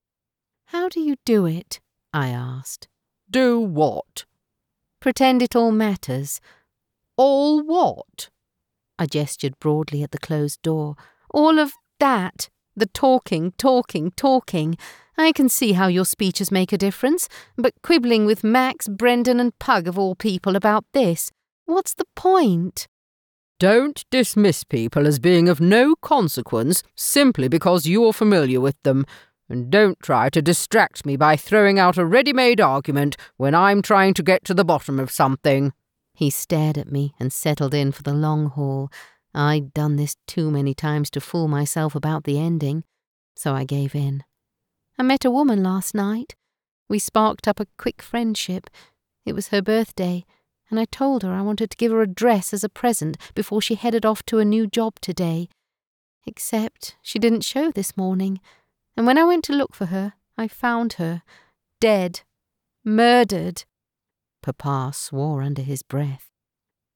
Narration
Mon accent anglais neutre est particulièrement adapté aux marchés internationaux.
Ma voix est naturelle et chaleureuse, tout en étant résonnante et autoritaire.
Micro Audio Technica AT2020